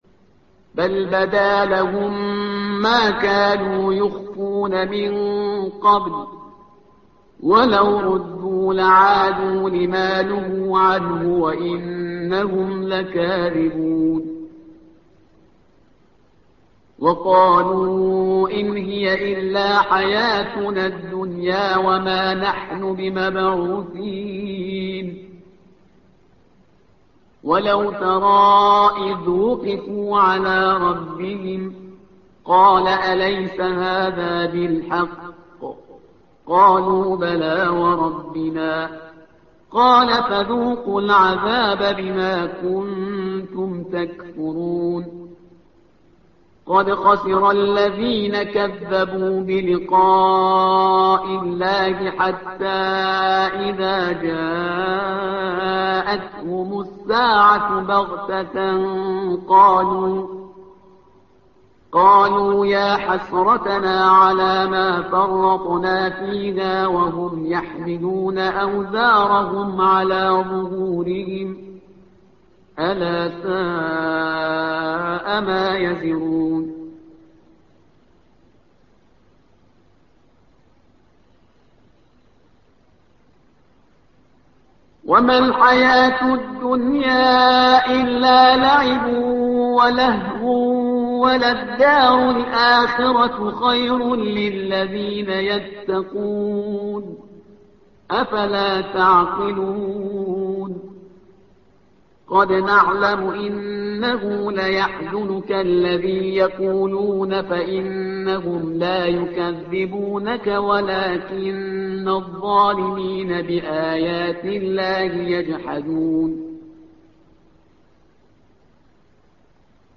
الصفحة رقم 131 / القارئ